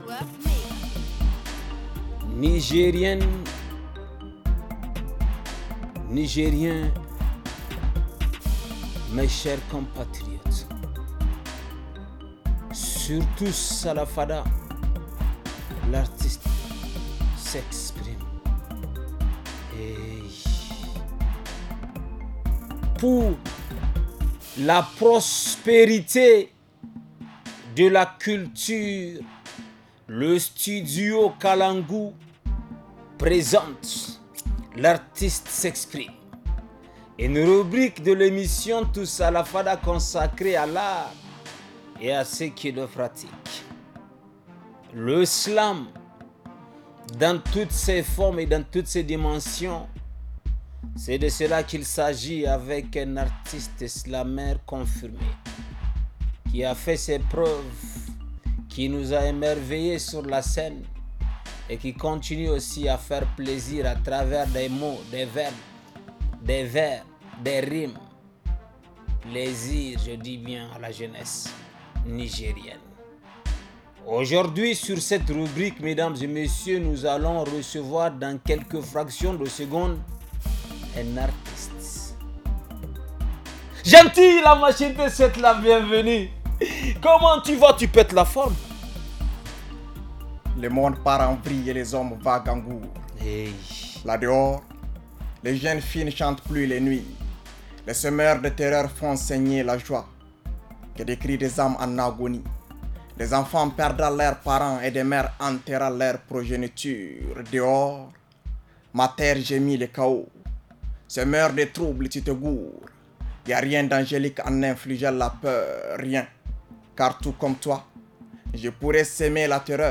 reçoit l’artiste slameur